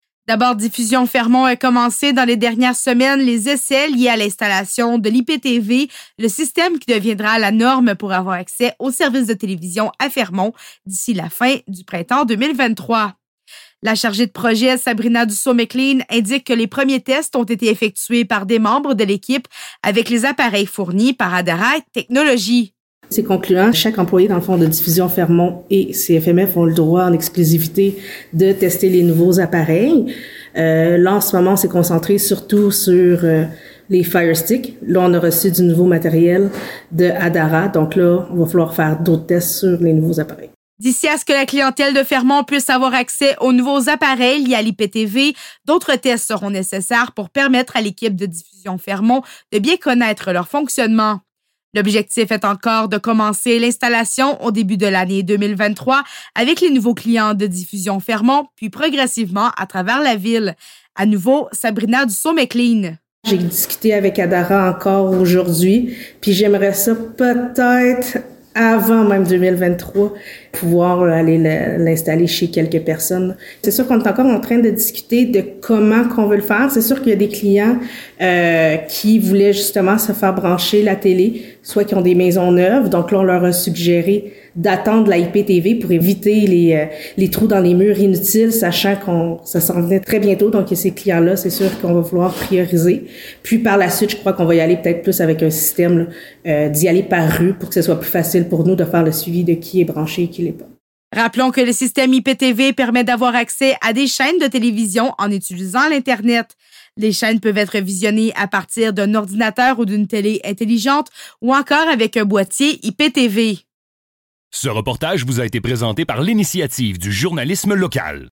Reportage-Diffusion-Fermont-iptv-premiers-tests-ID-IJL.mp3